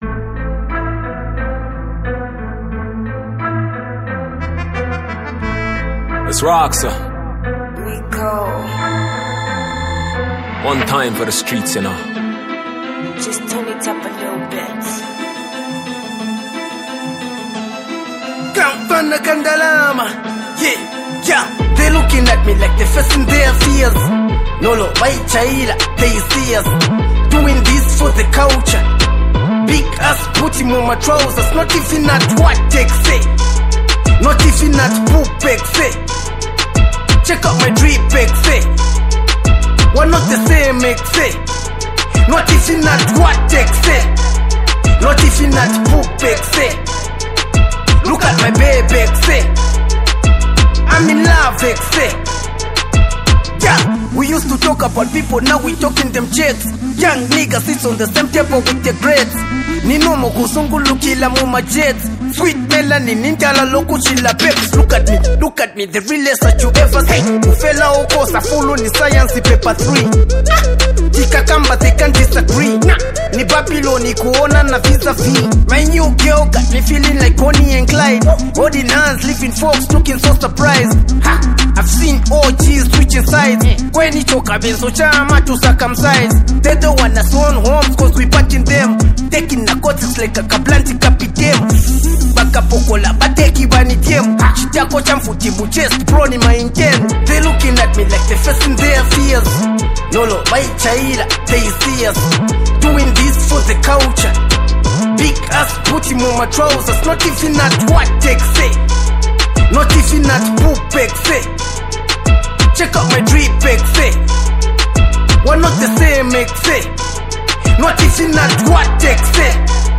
giving it a mix of smoothness and intensity.